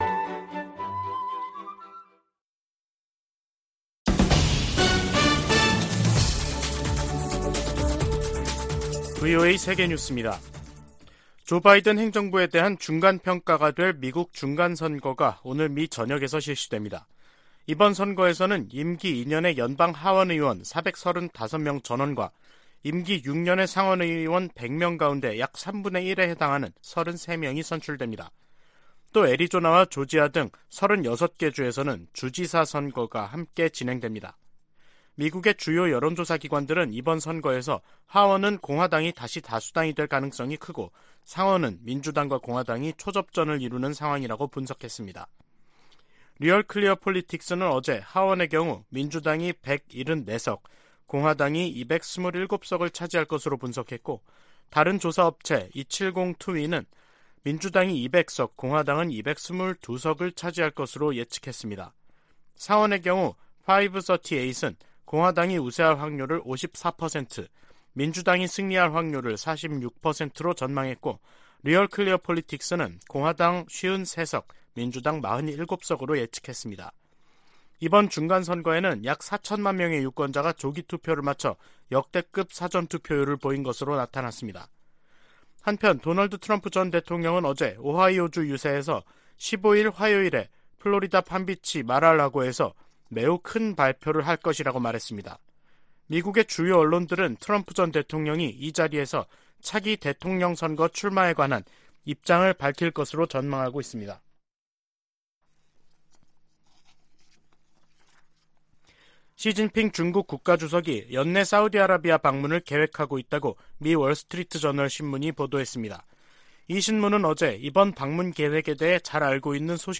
VOA 한국어 간판 뉴스 프로그램 '뉴스 투데이', 2022년 11월 8일 2부 방송입니다. 미국에서 임기 2년의 연방 하원의원 435명 전원과 임기 6년의 연방 상원의원 3분의 1을 선출하는 중간선거 투표가 실시되고 있습니다. 미 국무부는 유엔 안보리에서 북한에 대한 제재와 규탄 성명 채택을 막고 있는 중국과 러시아를 정면으로 비판했습니다. 유럽연합은 북한의 잇단 미사일 도발이 전 세계에 심각한 위협이라며 국제사회의 단합된 대응을 촉구했습니다.